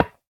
Minecraft Version Minecraft Version snapshot Latest Release | Latest Snapshot snapshot / assets / minecraft / sounds / block / bone_block / break2.ogg Compare With Compare With Latest Release | Latest Snapshot